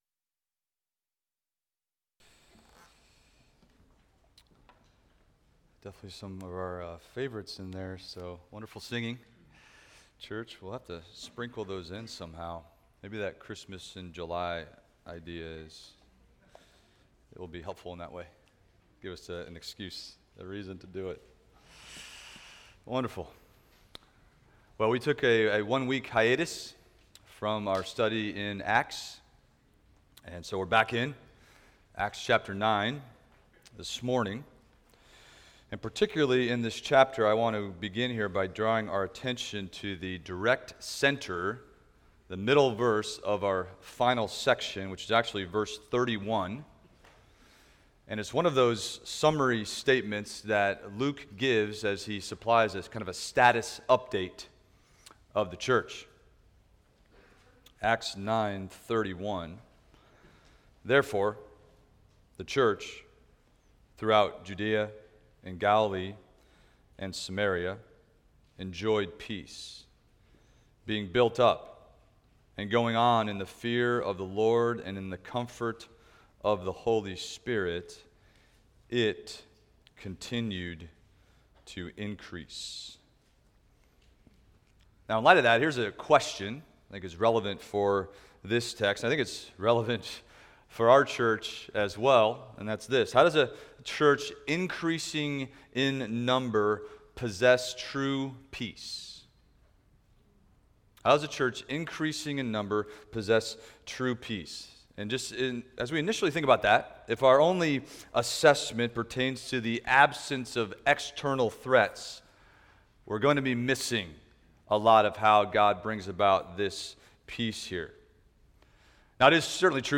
Expository Preaching from the Psalms – Psalm 119:97-104 - Treasuring the Work of the Word